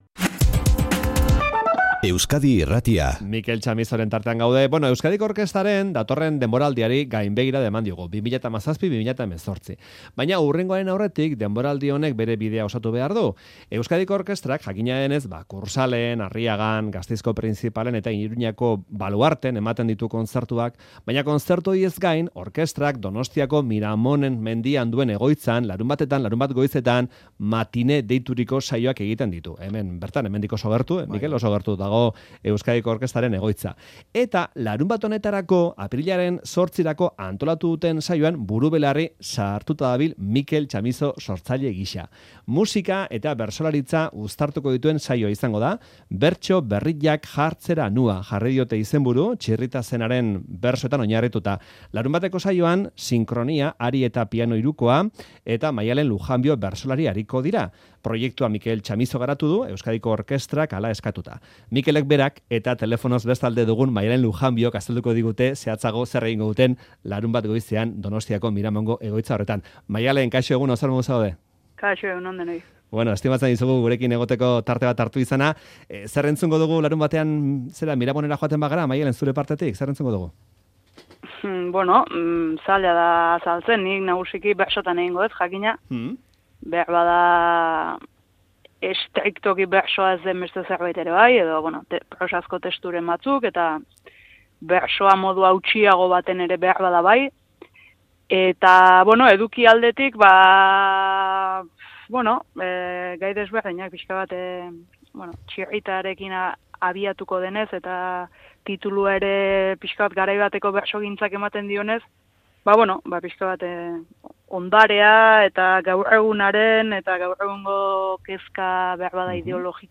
Fakorian izan ditugu musikaria bera eta Maialen Lujanbio bertsolaria.